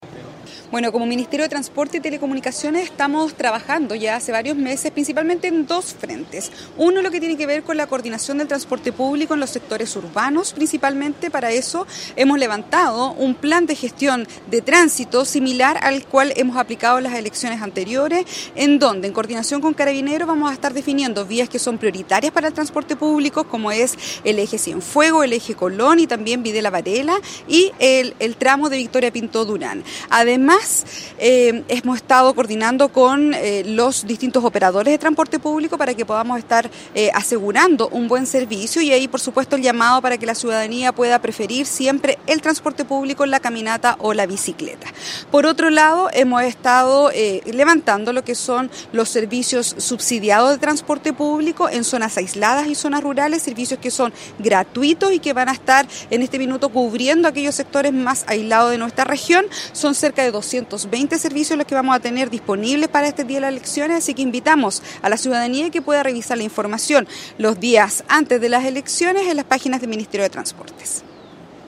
COORDINACION-PLEBISCITO-Alejandra-Maureira-Seremi-de-Transportes.mp3